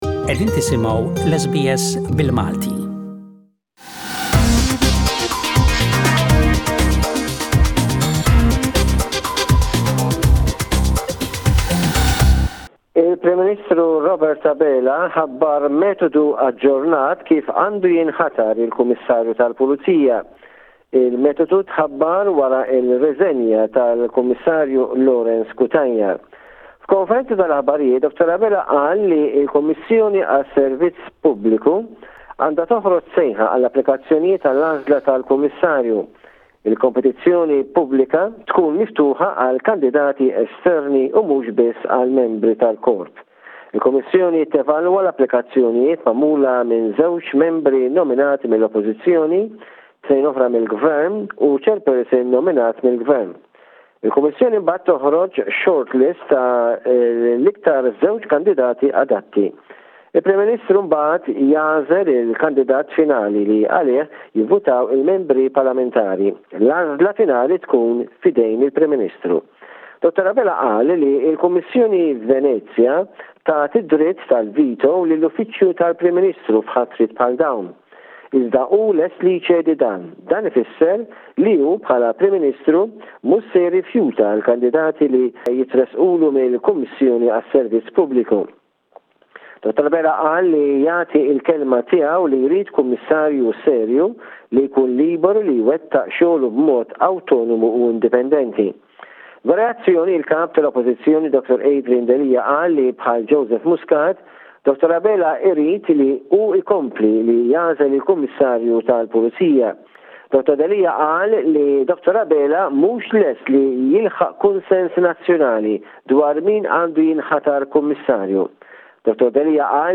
SBS Maltese